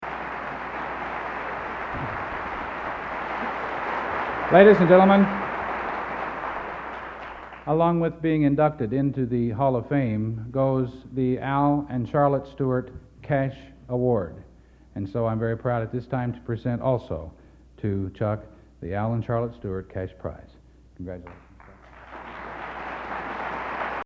Collection: End of Season, 1986
Genre: | Type: Director intros, emceeing |End of Season